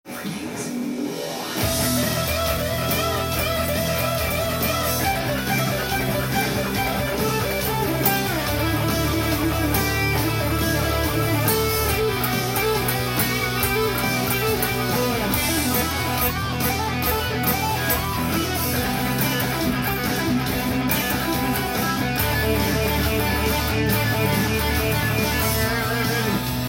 エレキギターで弾ける【カンタン速弾きハードロックsolo】tab譜と作り方
以上のポイントを踏まえながら　このカラオケに合わせてギターソロを弾いてみました。
速弾きギターソロ例
keyEm　でのソロ例でした。
沢山音符を弾いていますので上手そうな雰囲気が出ていますね。
hayabiki.solo_.tab_.m4a